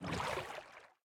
Minecraft Version Minecraft Version 1.21.5 Latest Release | Latest Snapshot 1.21.5 / assets / minecraft / sounds / entity / boat / paddle_water4.ogg Compare With Compare With Latest Release | Latest Snapshot
paddle_water4.ogg